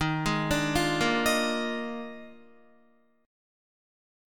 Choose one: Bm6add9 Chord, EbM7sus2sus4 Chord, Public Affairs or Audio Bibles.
EbM7sus2sus4 Chord